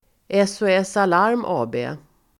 Ladda ner uttalet
SOS Alarm AB förkortning, SOS Uttal: [eso:'es:] Se film Definition: Samhällets olycks- och säkerhetstjänst (the public emergency services) Förklaring: SOS Alarm AB nås genom telefonnumret 112, dit man kan ringa om man behöver få hjälp av t ex ambulans, brandkår eller polis.